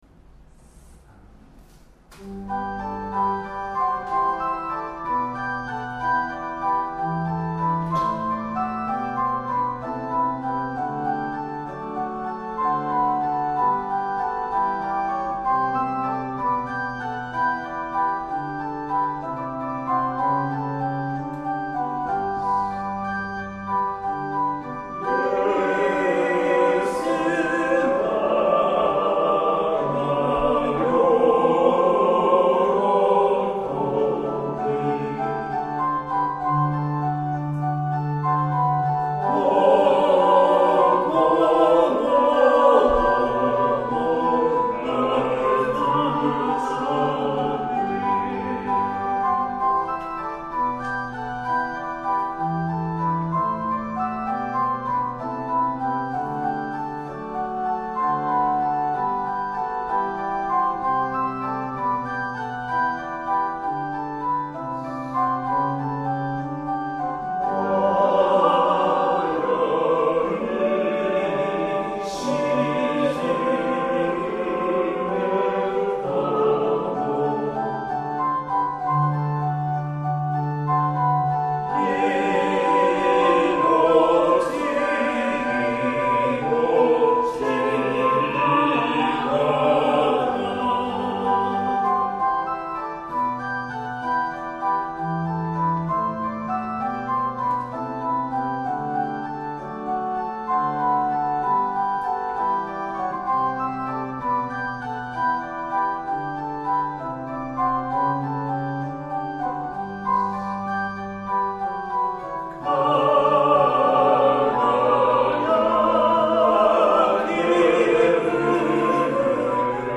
♪聖歌隊練習補助音源
Tonality = G　Pitch = 440　Temperament =Equal
1　 Organ with reverberation (S-H)